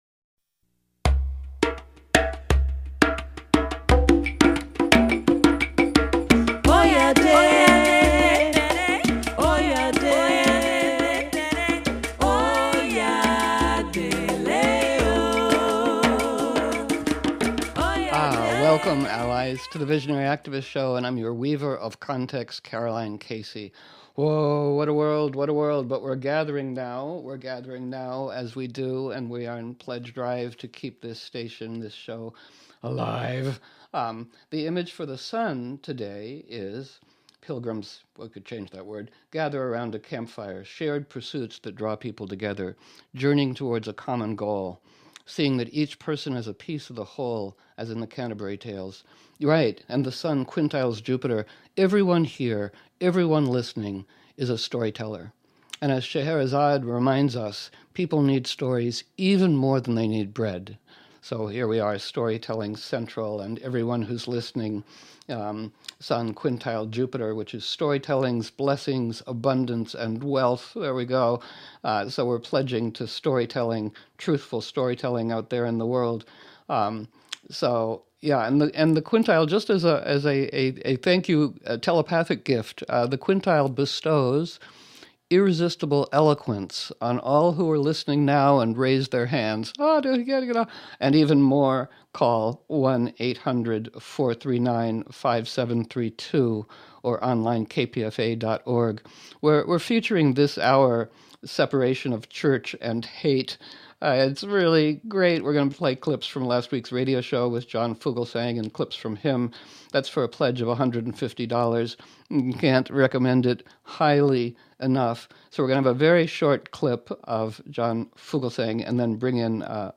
The Visionary Activist Radio Show - Coyote Network News